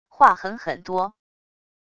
划痕很多wav音频